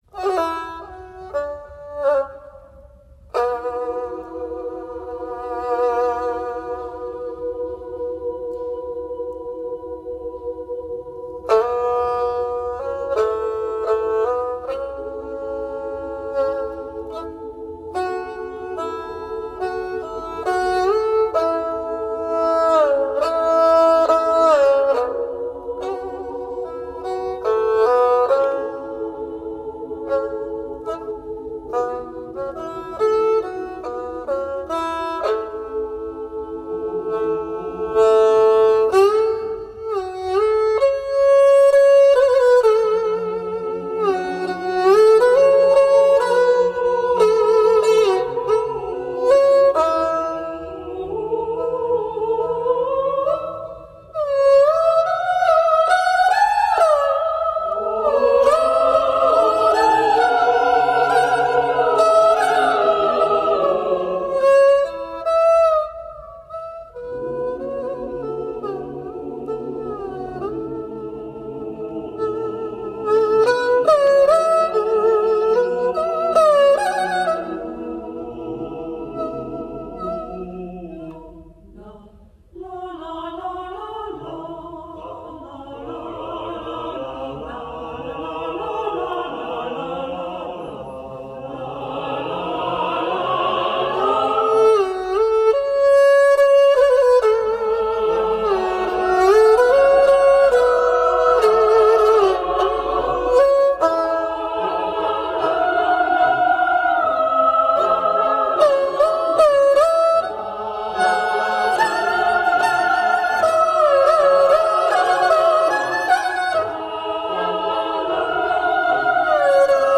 清新恬静的古典器乐配以新的曲风
音韵间耕织灵气